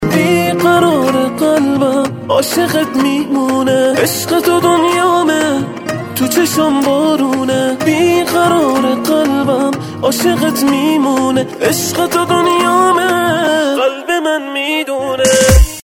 رینگتون احساسی